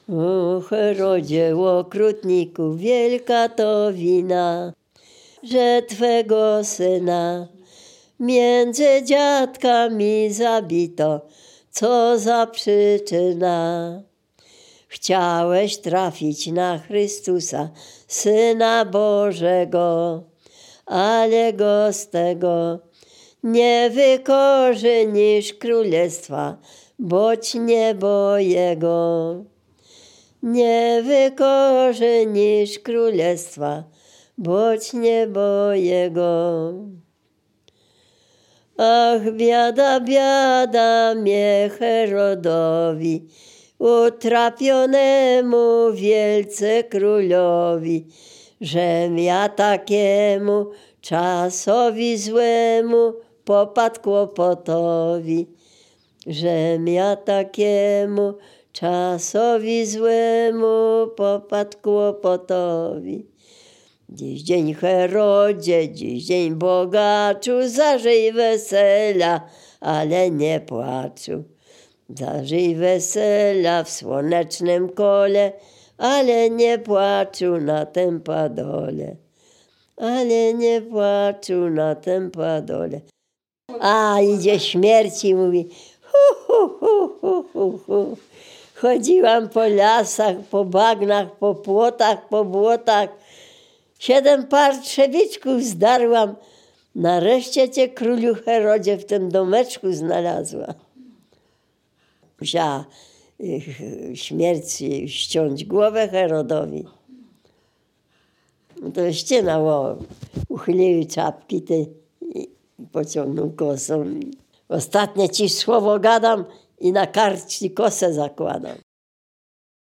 Dolny Śląsk, powiat bolesławiecki, gmina Nowogrodziec, wieś Zebrzydowa
Kolęda